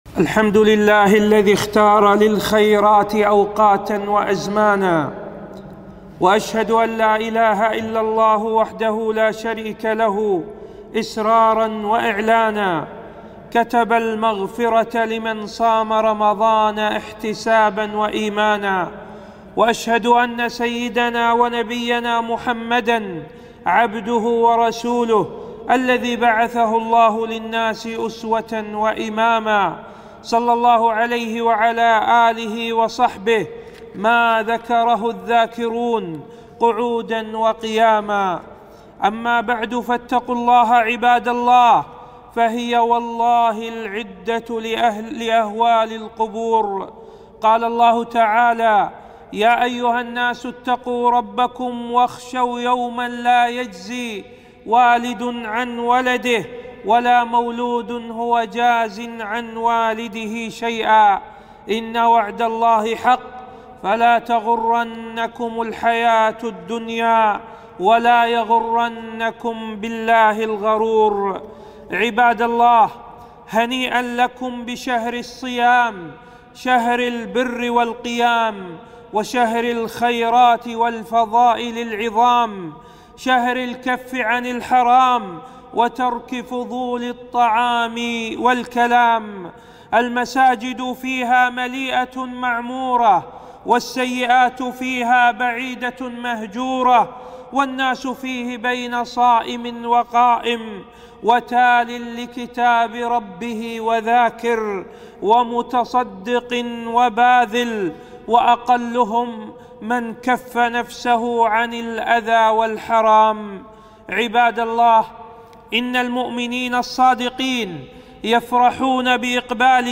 خطبة - التحذير من غزو المسلسلات في رمضان